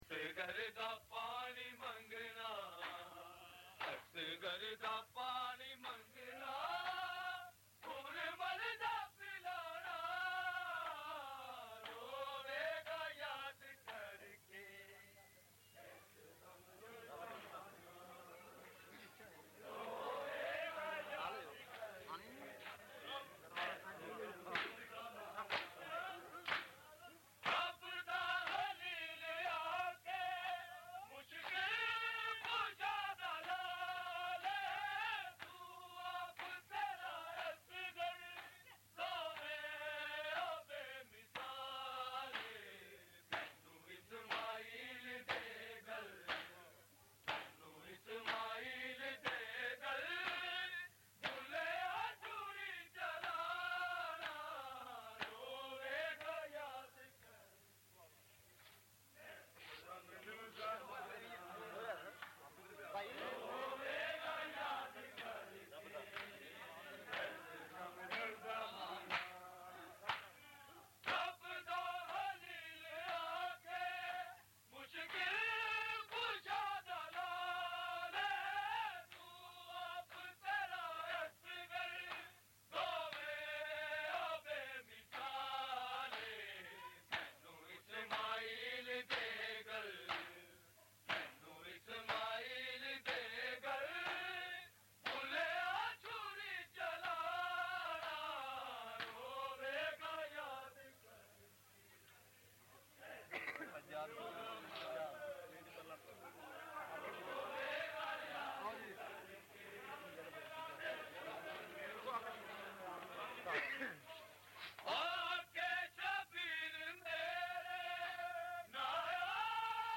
Katree Bawa, Chuna Mandee, Lahore
Recording Type: Live
Location: Lahore, Mochi Gaate